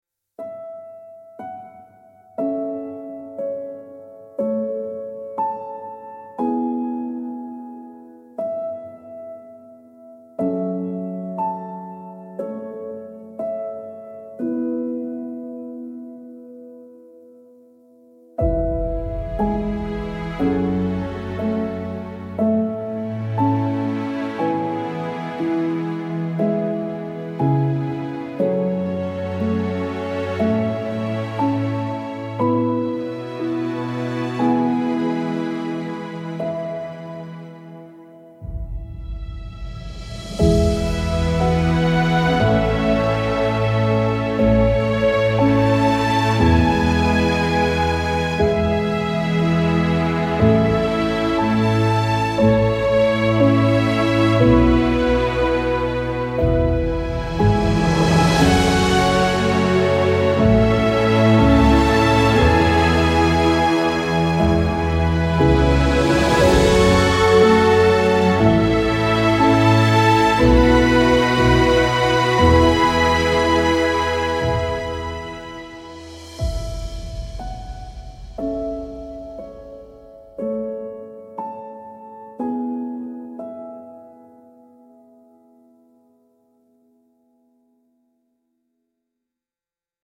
gentle cinematic underscore building from solo piano to full orchestra